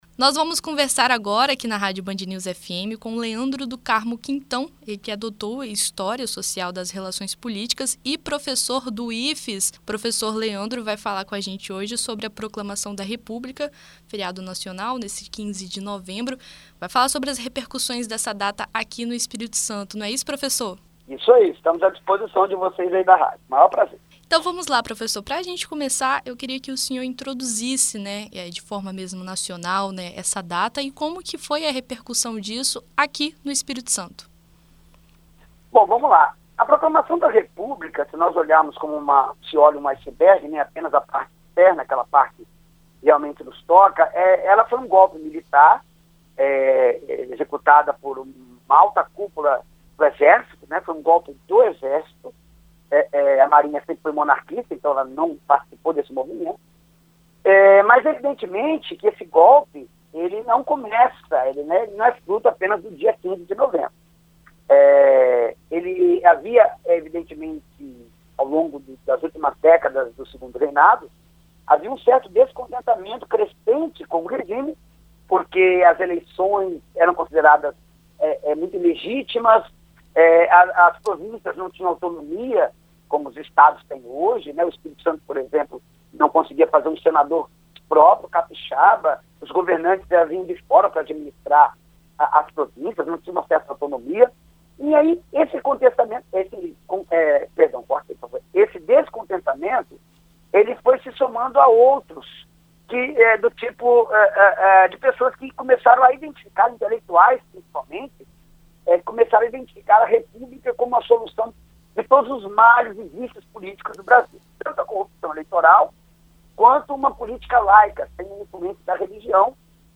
Em entrevista à BandNews FM Espírito Santo